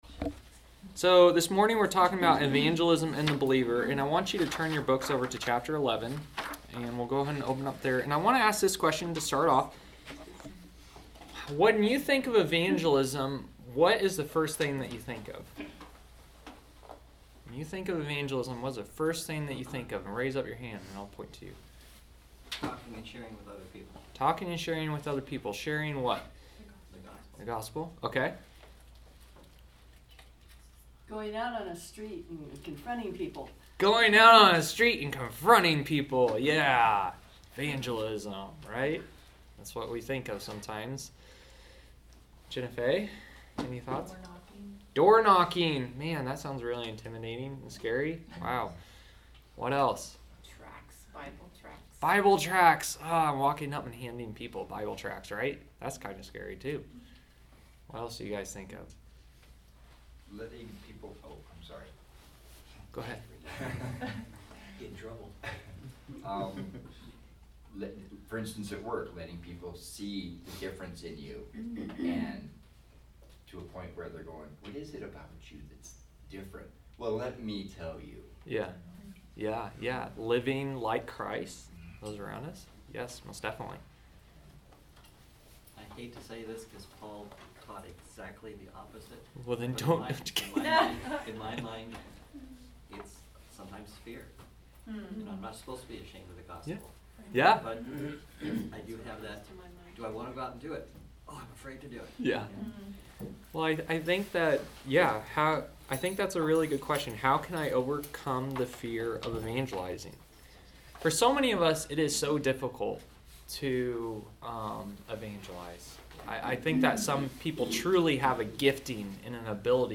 Sermons | New Hope Baptist Church